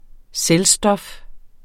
Udtale [ ˈsεl- ]